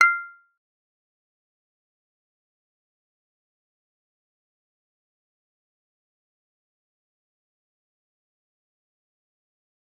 G_Kalimba-F6-f.wav